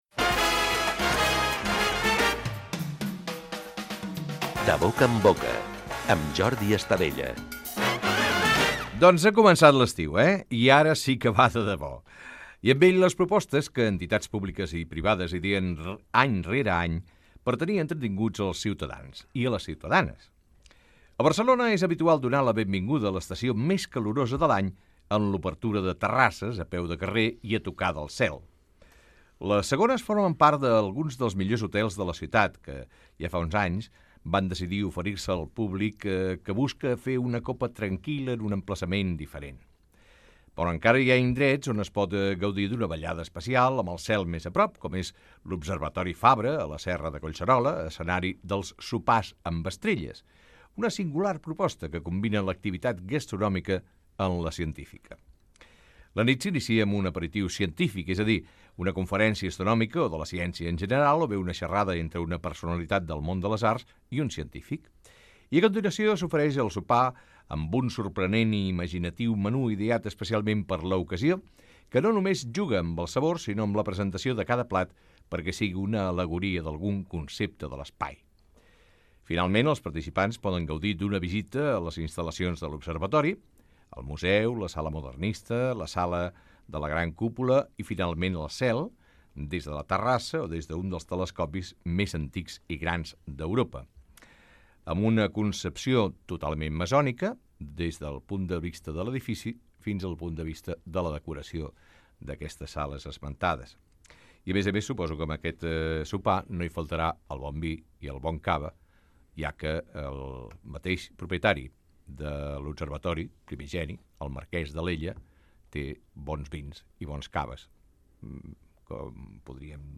Indicatiu del programa, presentació inicial, dedicada a l'arribada de l'estiu i les terrasses, sumari de continguts i tema musical
Fragment extret de l'arxiu sonor de COM Ràdio